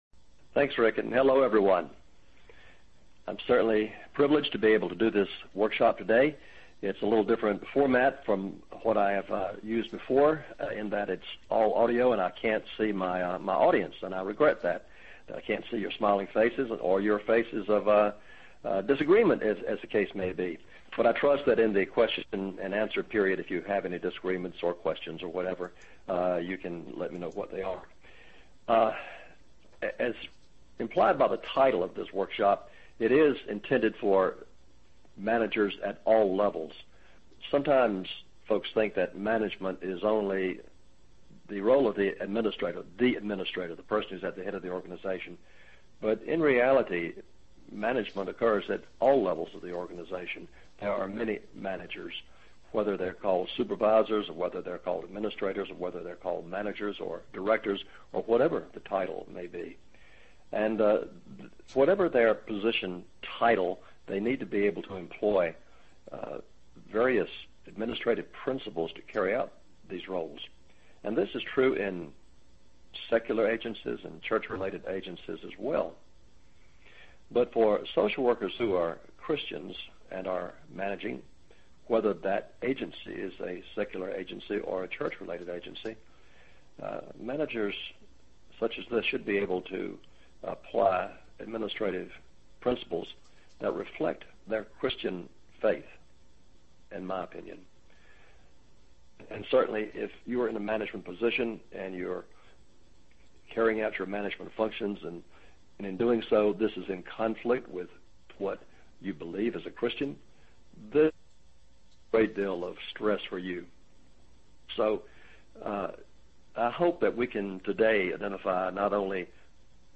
Presenter(s):